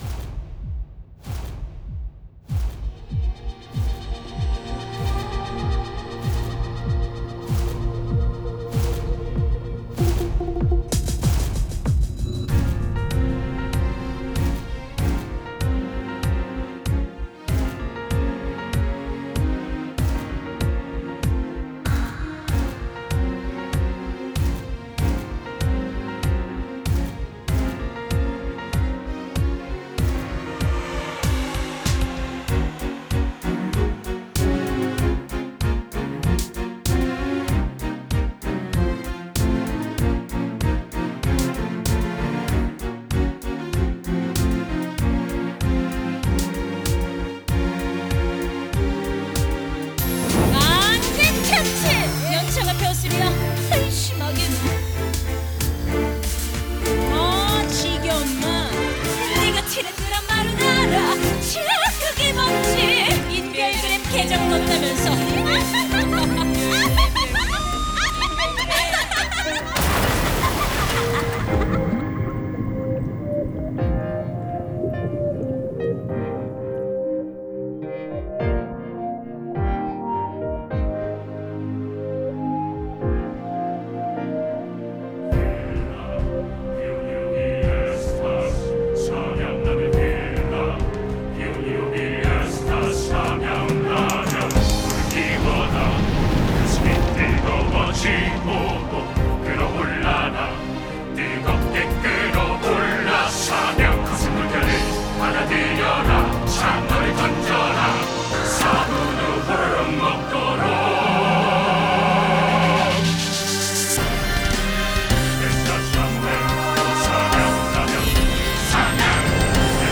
MR Ver2
(앙상블, 코러스, 불닭, 햄복이 목소리 포함)